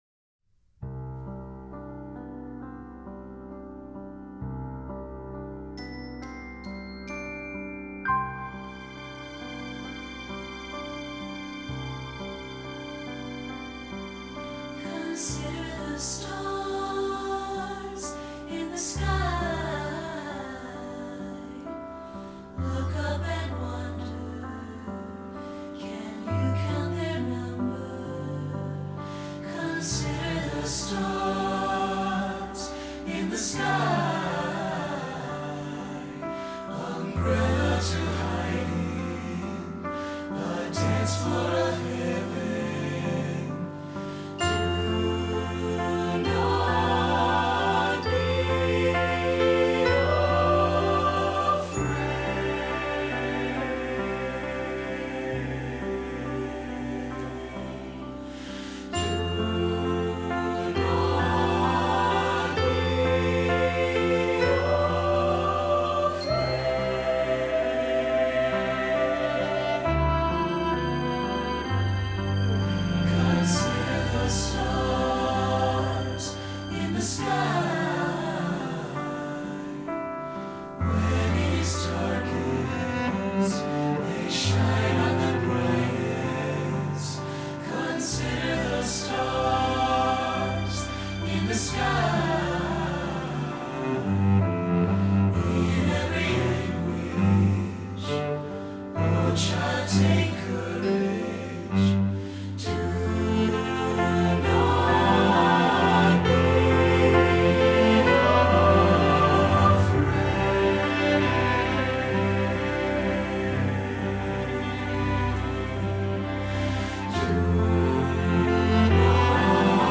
SATB
Choral Church